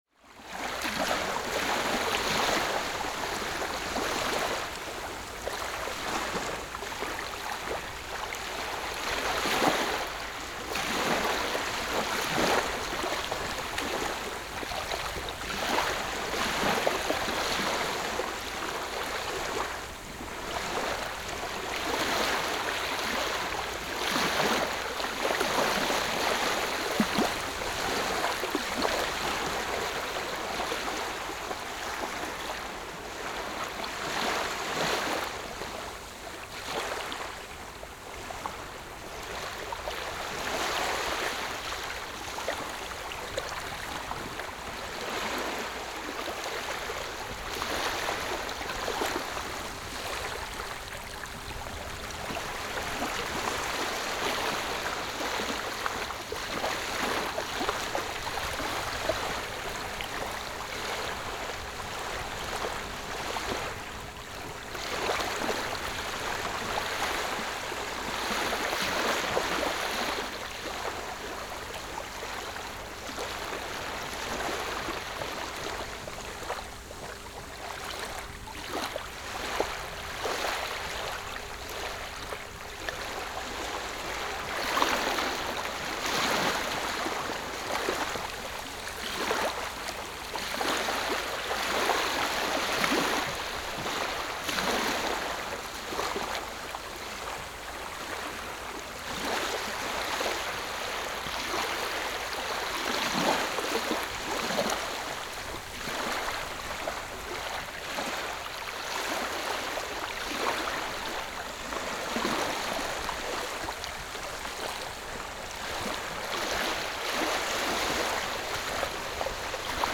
Je reprends alors le chemin de la plage avec le stéréo et le SX-R4 pour faire quelques sons de vagues et il faut le dire, s’isoler un peu et visiter le coin.
Le lieu est à peine calme, les bateaux ont repris la pêche, les enfants jouent sur le sable, il me faut un long moment avant de pouvoir trouver l’endroit idéal, mais à marée haute, les vagues sont trop fortes par rapport à ce matin.
La République des enfants – 55 / 4 w4 – stéréo MS décodé L&R